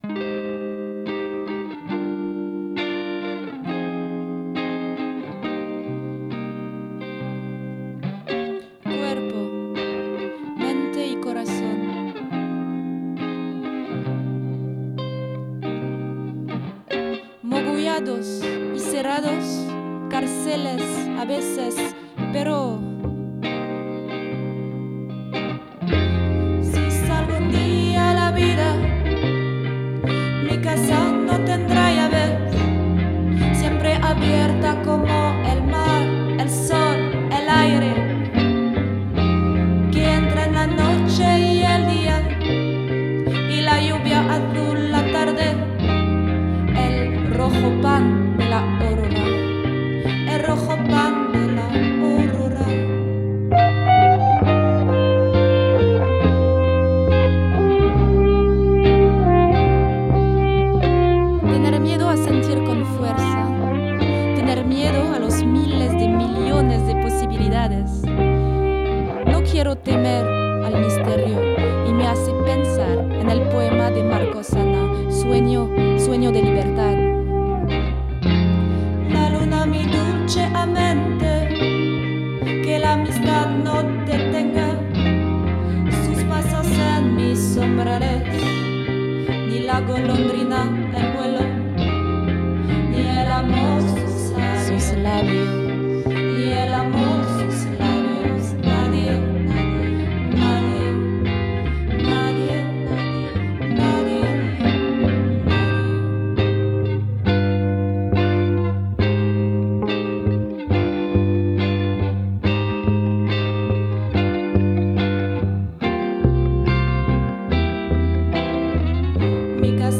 Démo.